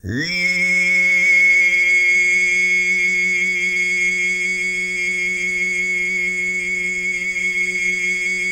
TUV1 DRONE02.wav